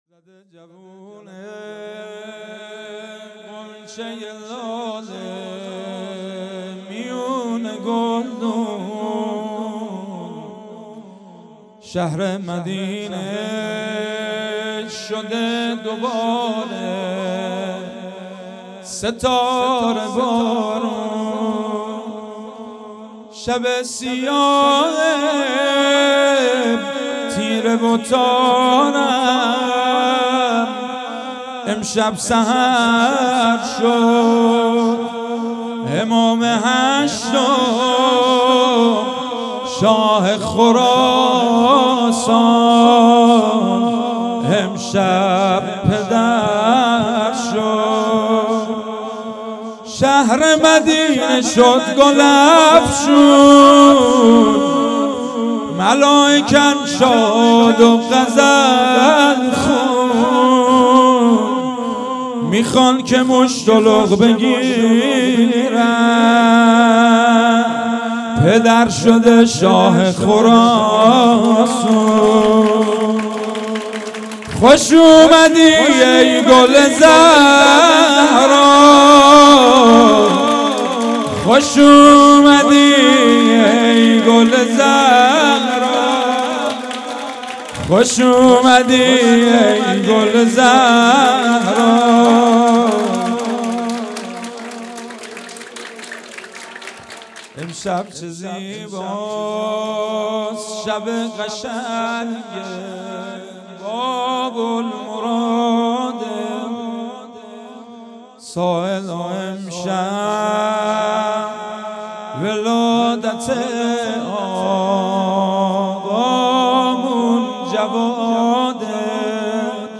شعر خوانی - زده جوونه غنچه لاله میون گلدون
جشن ولادت امام جواد علیه السلام